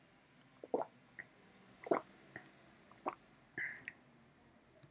swallow-water.wav